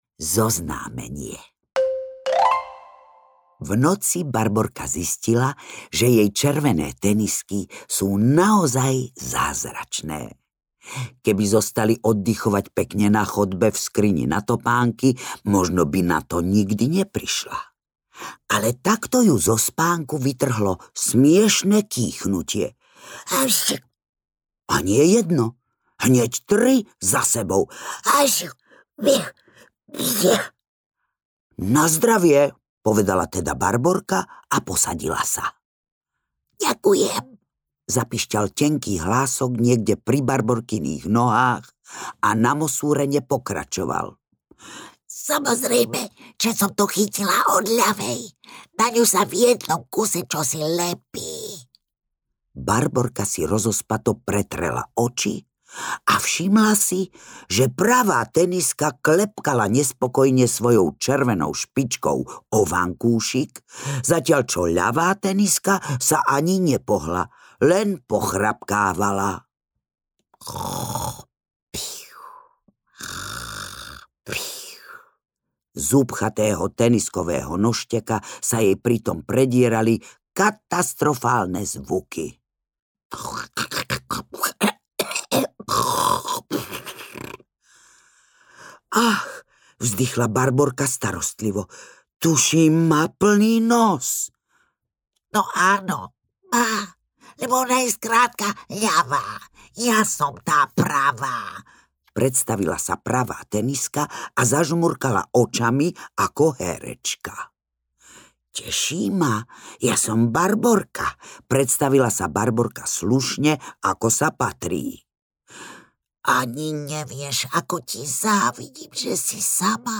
Červené tenisky audiokniha
Ukázka z knihy
• InterpretZuzana Kronerová